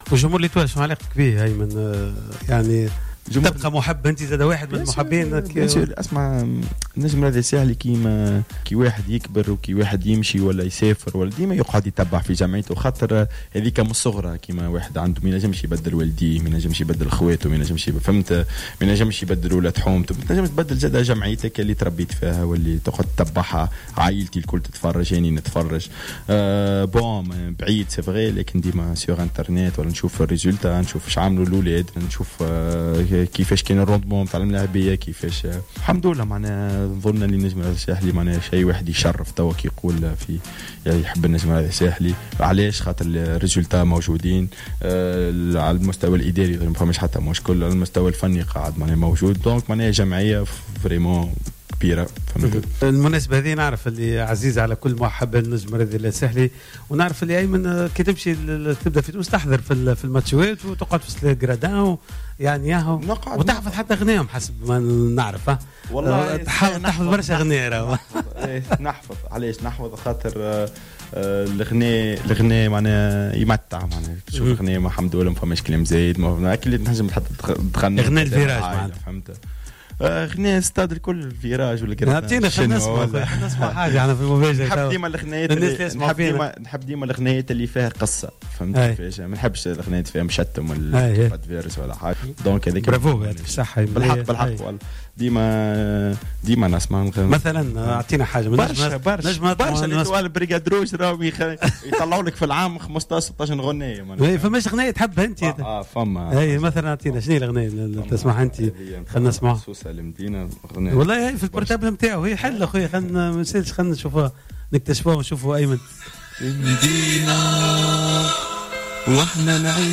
في عيد ميلاد النجم الساحلي : أيمن عبد النور يغني لجماهير الفريق
أهدى اللاعب التونسي لفريق فالنسيا الإسباني ايمن عبد النور خلال حصة cartes sur table التي بثت اليوم مباشرة من مدينة فالنسيا أغنية "سوسة المدينة" إلى فريقه السابق النجم الرياضي الساحلي بمناسبة 91 سنة على تأسيس الجمعية معربا أنه يستمع دائما إلى أغاني جماهير النجم.